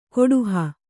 ♪ koḍuha